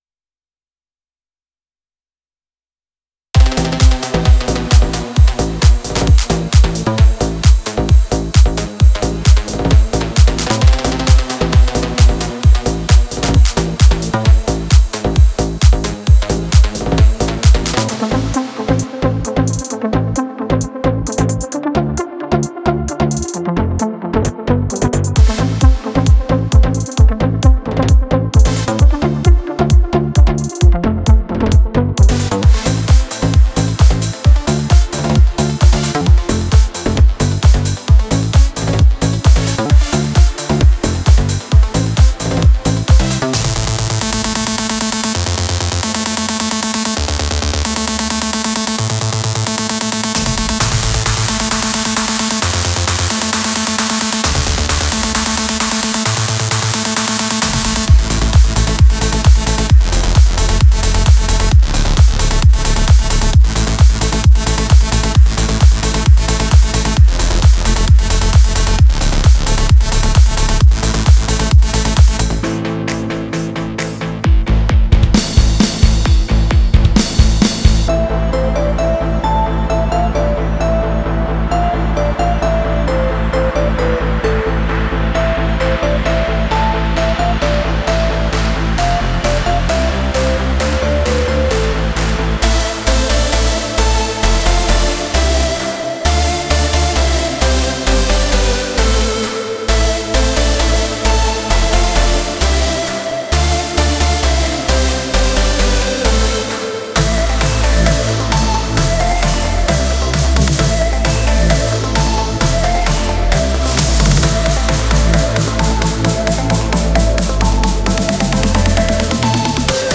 טראנס חדש שעשיתי